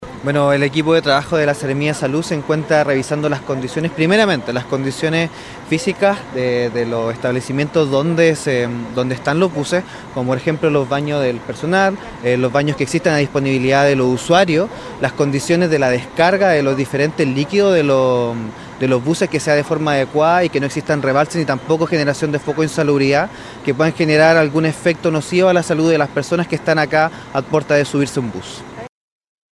En la misma oportunidad, estuvo presente el seremi (s) de Salud de Atacama, Bastian Hermosilla, el cual detalló el tipo de trabajo de fiscalización que se desarrolla por parte de los funcionarios de su cartera: